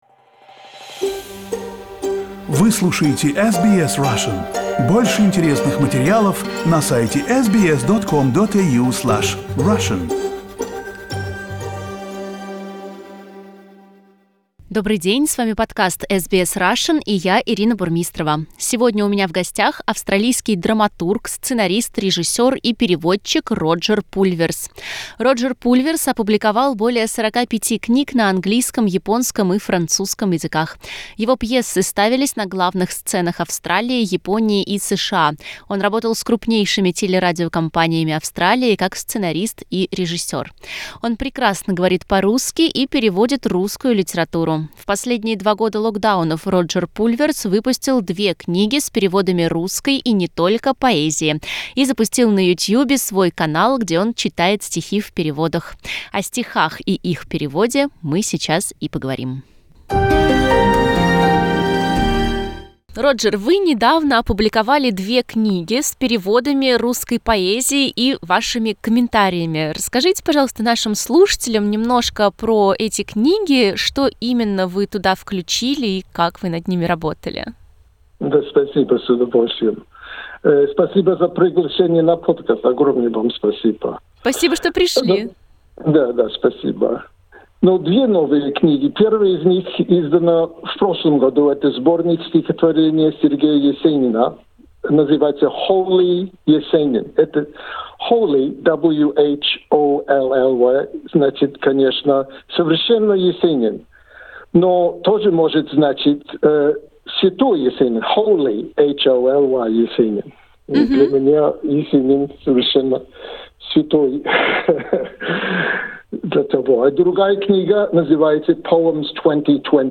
В этом подкасте Роджер Пульверс читает стихи в своем переводе на английский: «Песнь о собаке» Сергея Есенина; «Муза» Анны Ахматовой; «Я помню чудное мгновенье» Александра Пушкина.